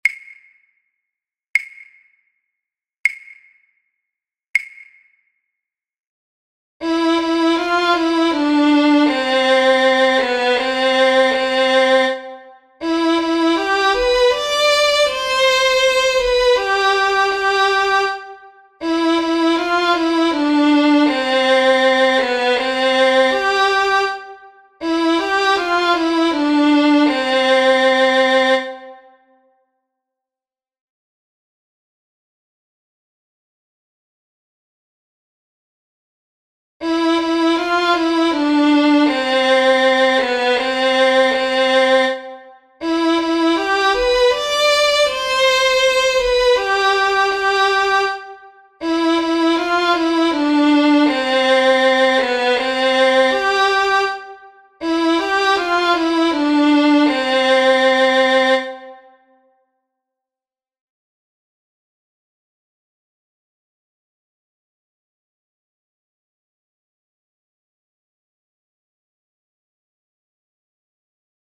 Tinsagu nu Hana melody image